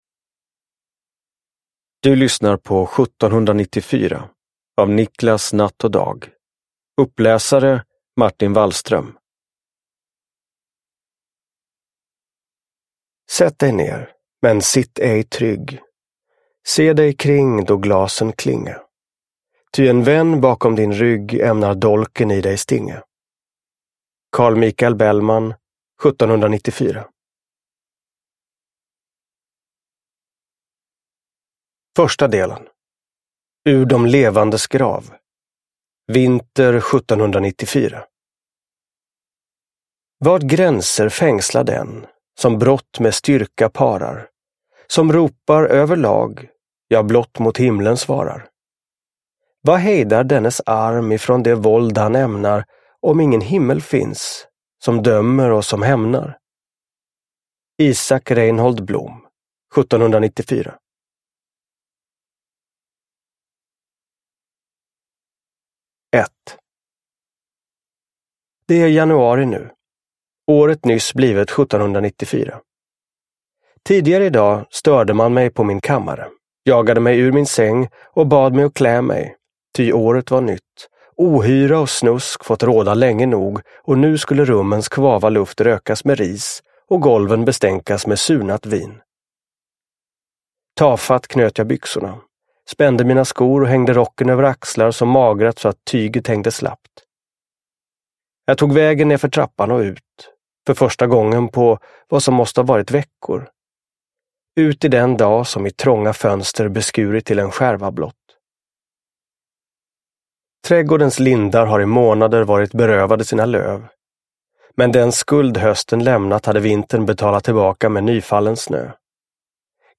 Uppläsare: Martin Wallström
Ljudbok